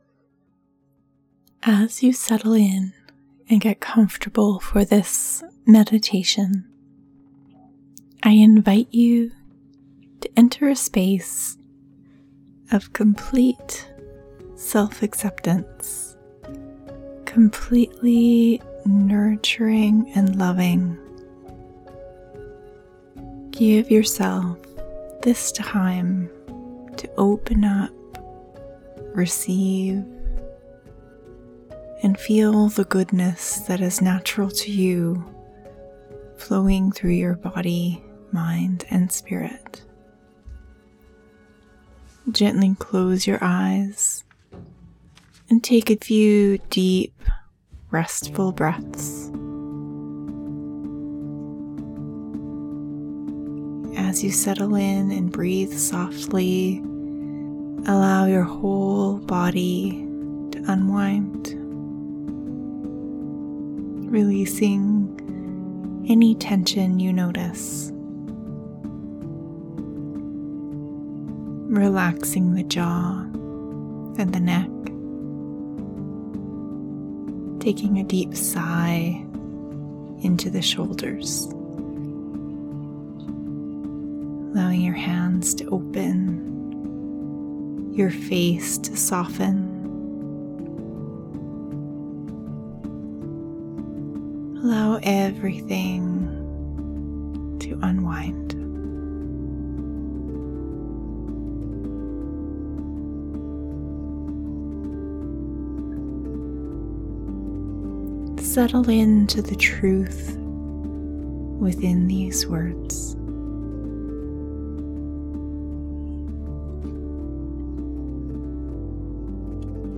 Self Acceptance Meditation
Music Credit: ‘Musing’ by Acedis